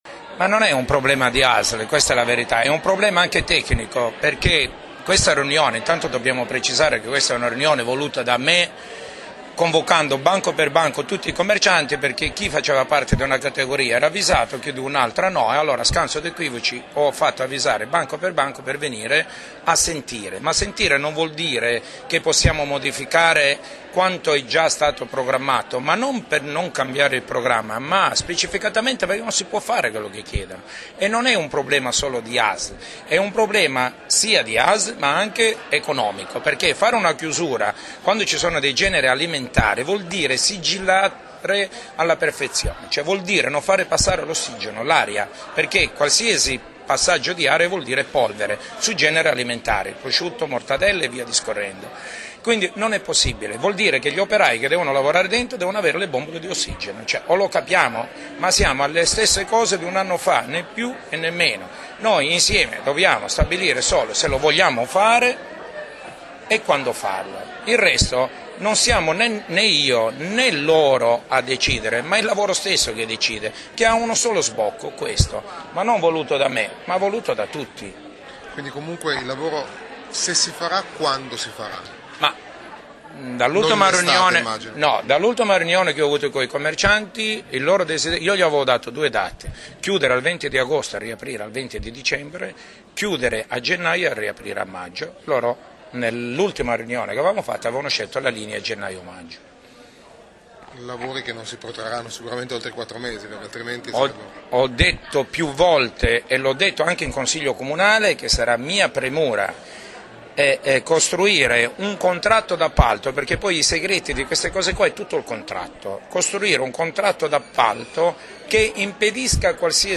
Una breve intervista all'Assessore Fera cliccando